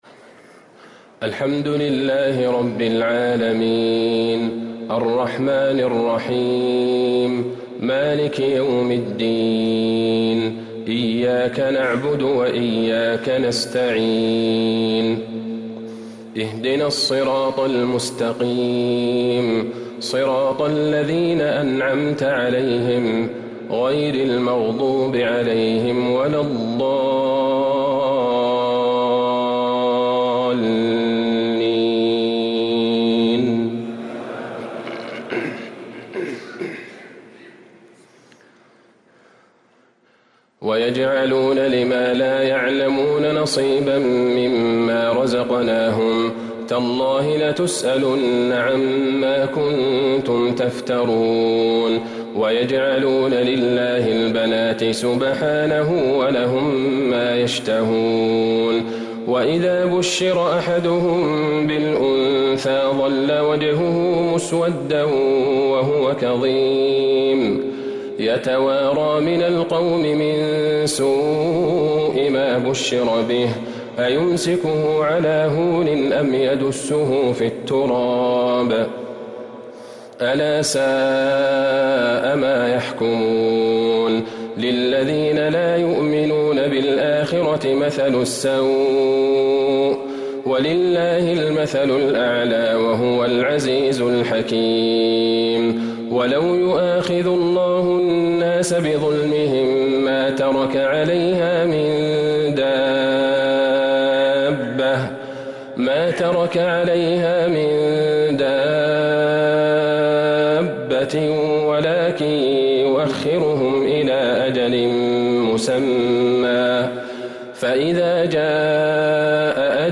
تراويح ليلة 19 رمضان 1444هـ من سورة النحل (56-119) | taraweeh 19th night Ramadan 1444H Surah An-Nahl > تراويح الحرم النبوي عام 1444 🕌 > التراويح - تلاوات الحرمين